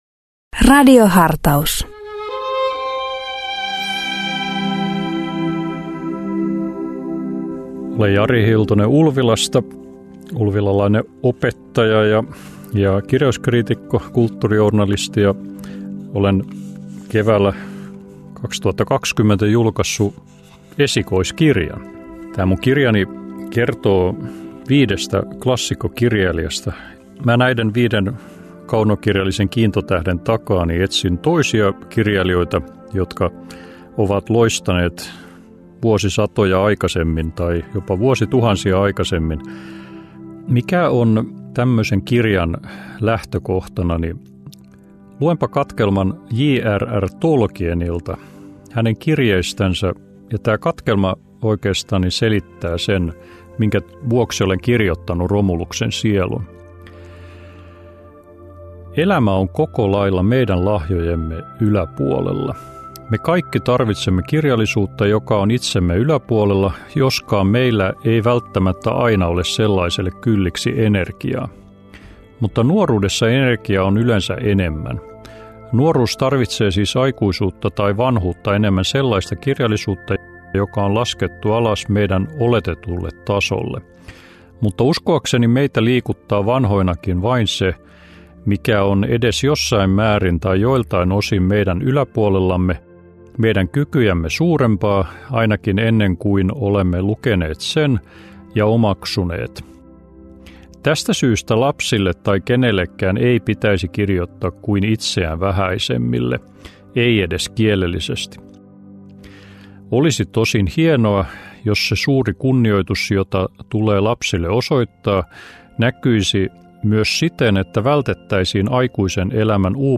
PostRadio Dei lähettää FM-taajuuksillaan radiohartauden joka arkiaamu kello 7.50. Radiohartaus kuullaan uusintana iltapäivällä kello 17.05.
Pääpaino on luterilaisessa kirkossa, mutta myös muita maamme kristillisen perinteen edustajia kuullaan hartauspuhujina.